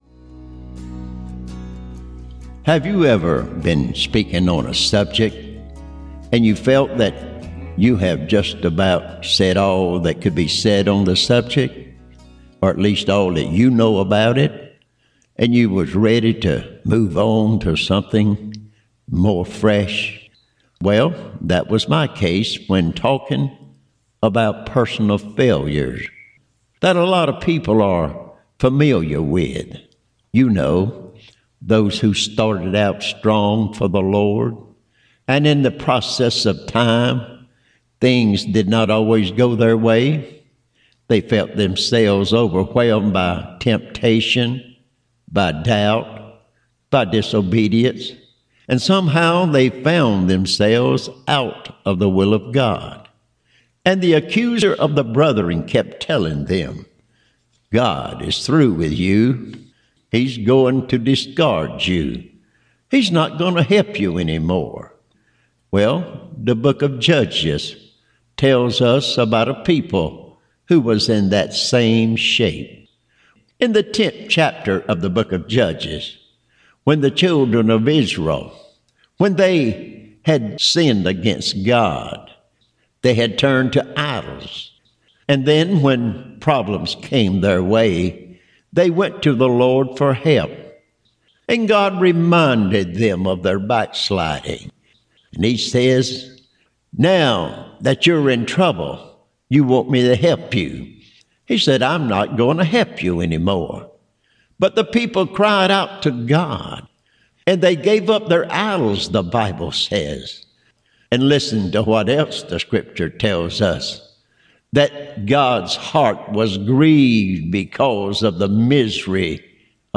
The last Lesson of a four part lesson